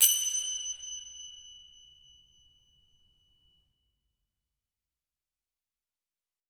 Perc  (1).aif